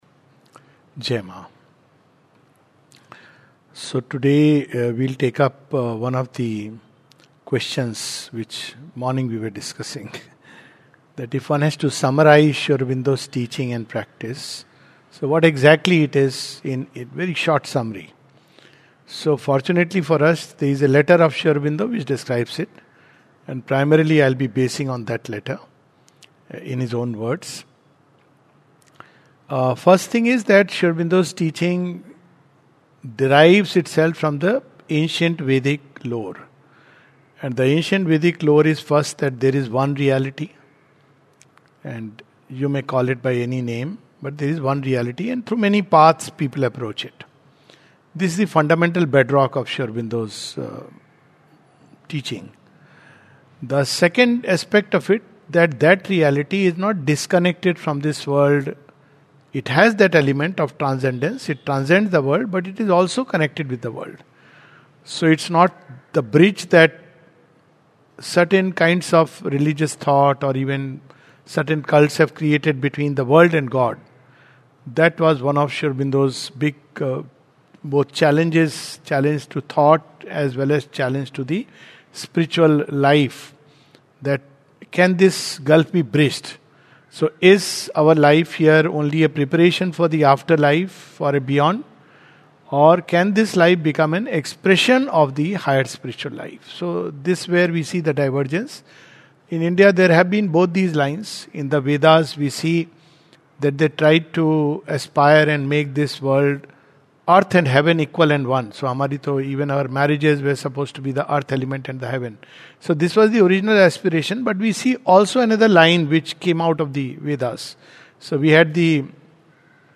We take up a passage by Sri Aurobindo summarizing his teachings and practice. A session at Matriniketan